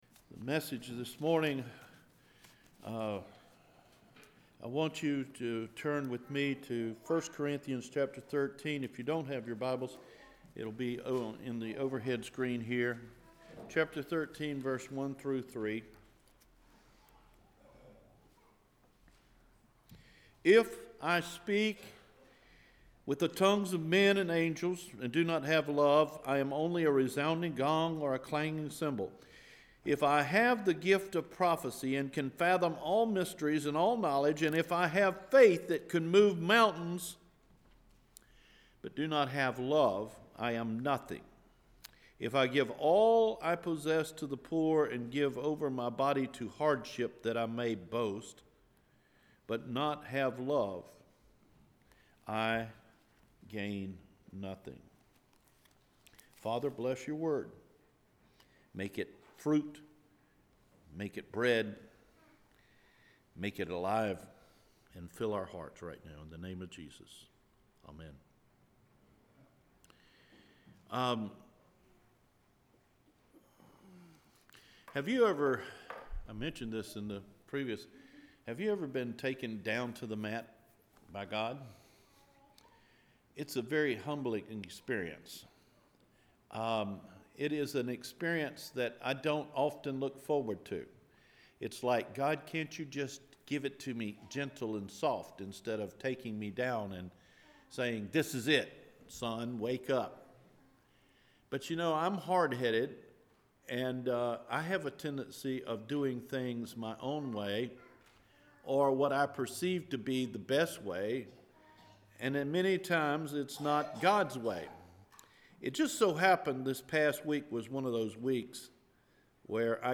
Without Love – January 14 Recorded Sermon